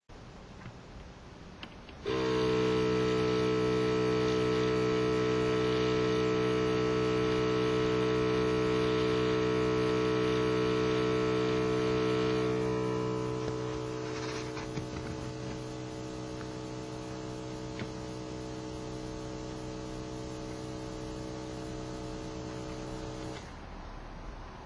こちらは、同じ消音ボックスで田宮の「コンパクトコンプレッサー」を作動させたときの動作音。
タミヤ コンパクトコンプレッサーの作動音（WMAファイル）
ハンドピースからはエアーを出した状態で、前半１３秒ぐらいまでがフタを開けた状態、その後はフタを閉めた状態での作動音です。